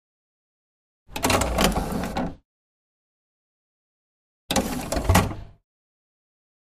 Player Dat, Motorized Door, Open, Close x2